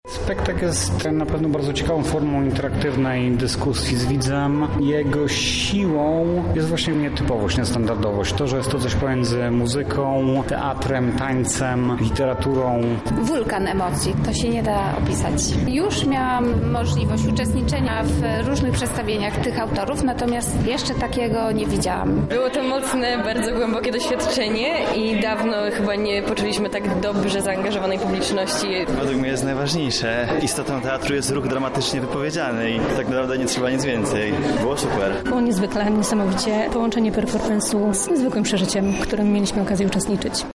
O wrażeniach i przemyśleniach dotyczących przedstawienia zapytaliśmy jego uczestników:
Ryt_Przejscia-relacja.mp3